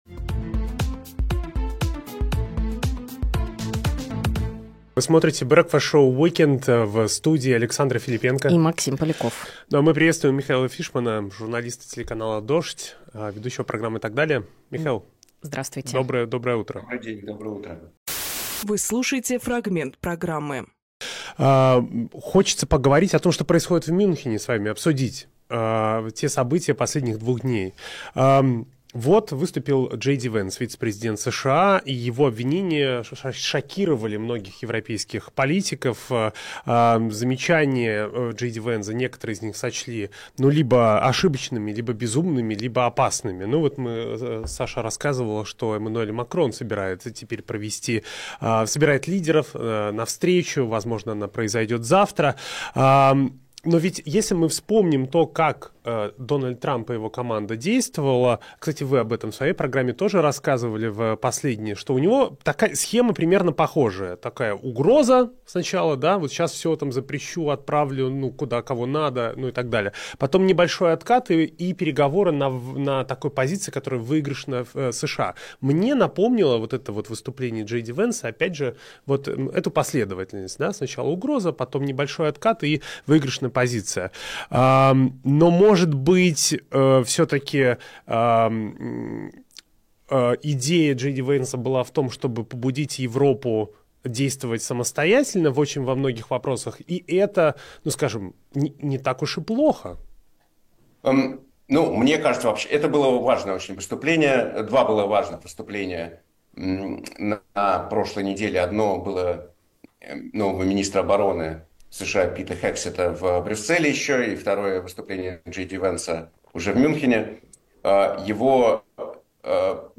Фрагмент эфира от 16.02